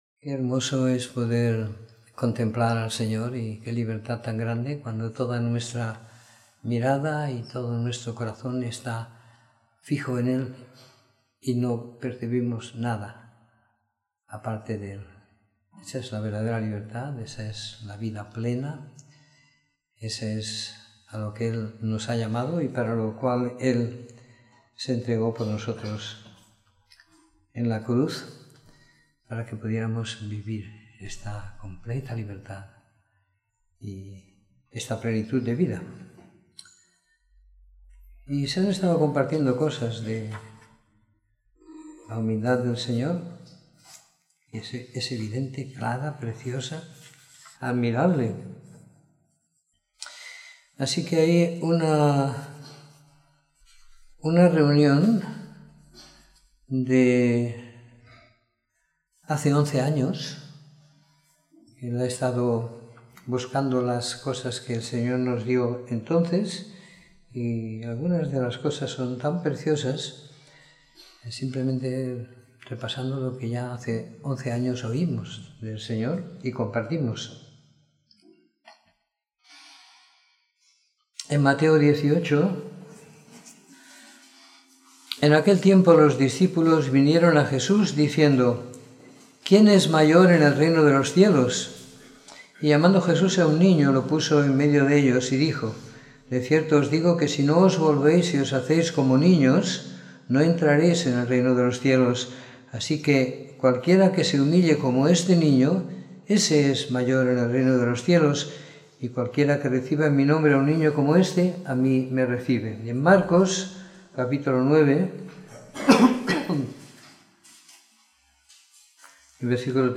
Domingo por la Tarde . 12 de Marzo de 2017